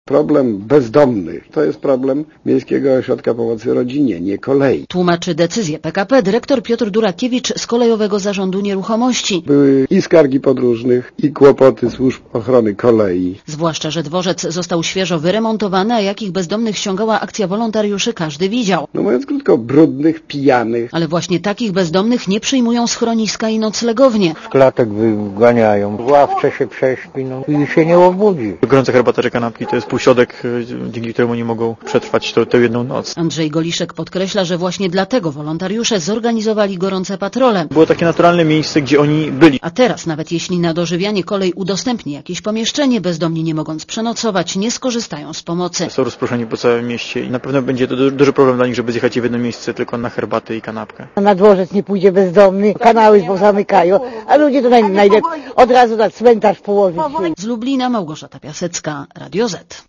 Posłuchaj relacji reporterki Radia Zet (231 KB)